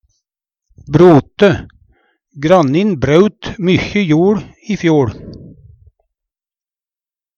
DIALEKTORD PÅ NORMERT NORSK brote dyrke jord Infinitiv Presens Preteritum Perfektum brøte bryt braut brøte Eksempel på bruk Grannin braut mykje joL i fjoL.